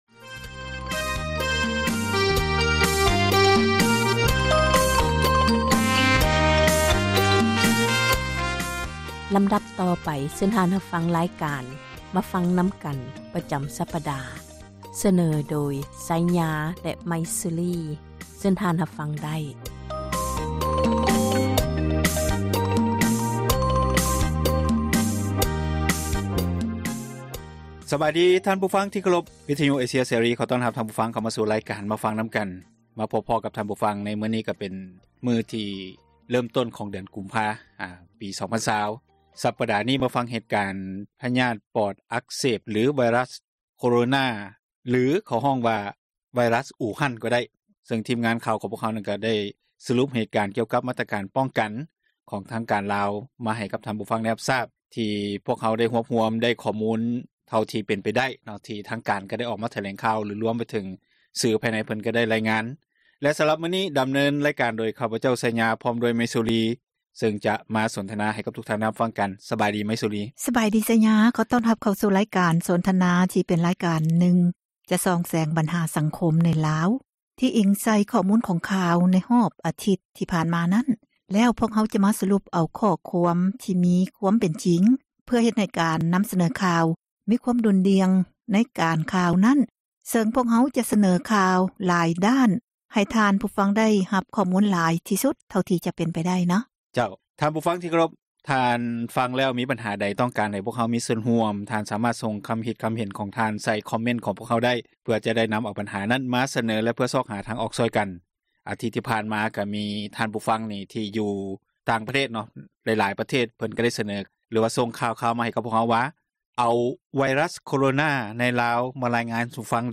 ແມ່ນຣາຍການສົນທະນາ ບັນຫາສັງຄົມ